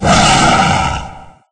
Monster2.ogg